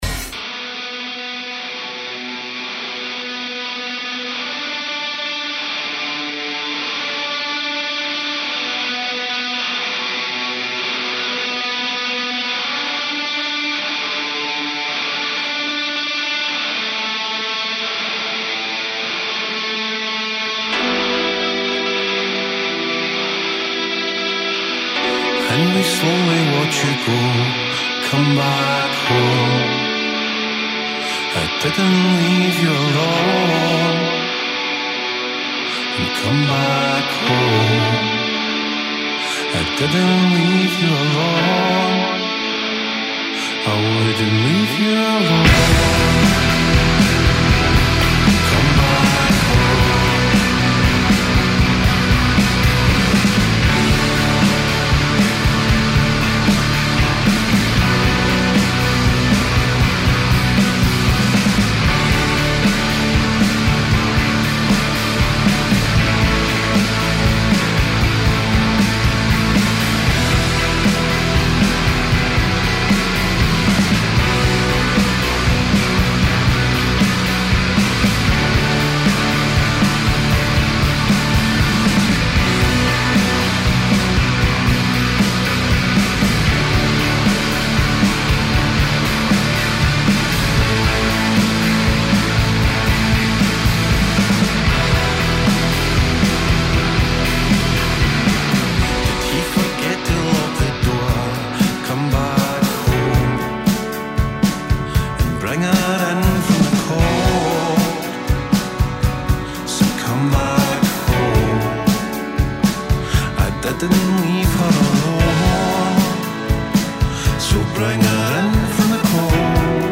Un’ora di divagazione musicale